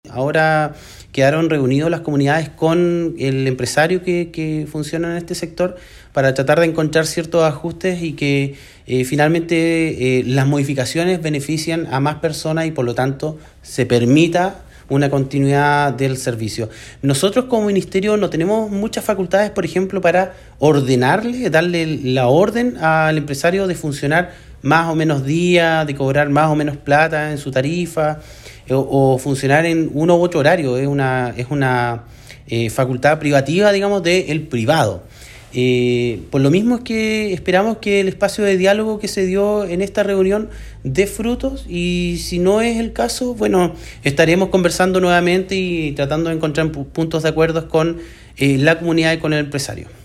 El Seremi Pablo Joost, indicó que se buscó llegar a un acuerdo con las comunidades y el empresario de transporte, para tratar de mantener el servicio, puesto que como Ministerio no tienen la atribución de exigir a los privados la prestación de un servicio.